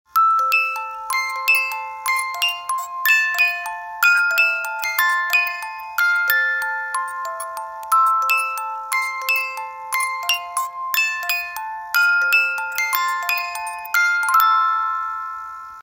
Divertido